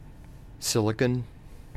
Ääntäminen
US
IPA : /ˈsɪlɪkən/